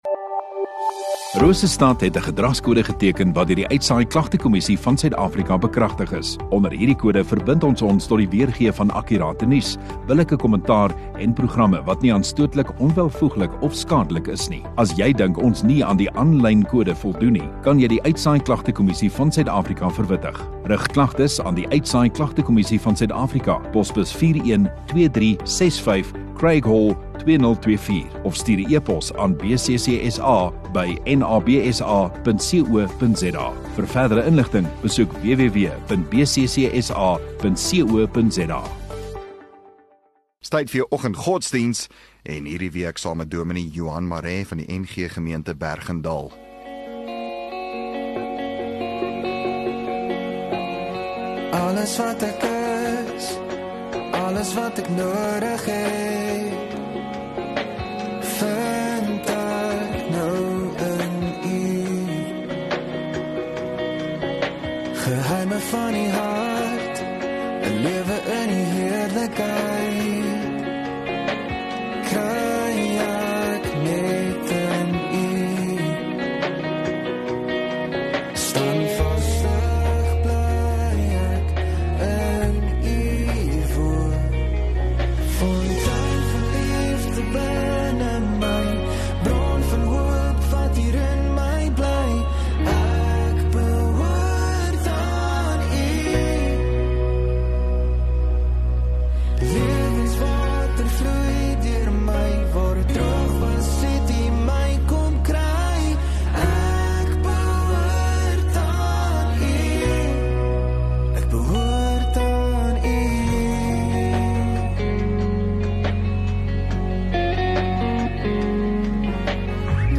13 Jun Vrydag Oggenddiens